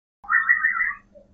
wobble_01.mp3.mp3